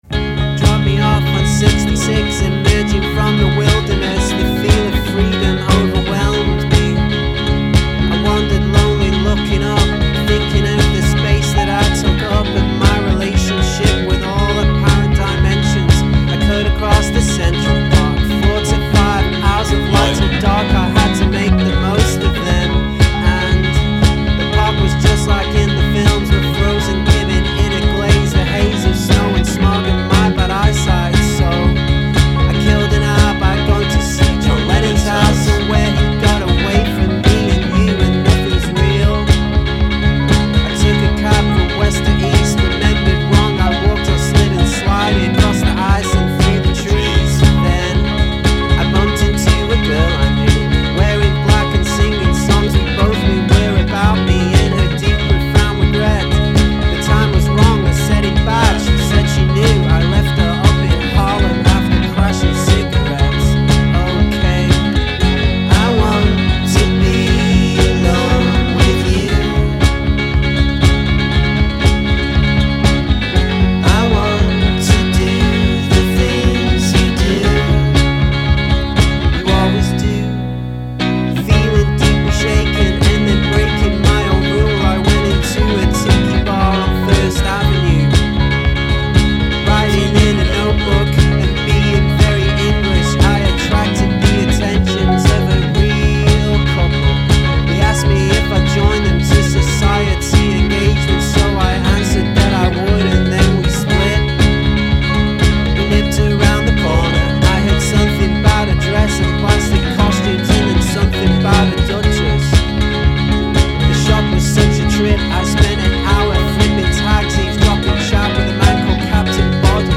Stripped back and naked in its approach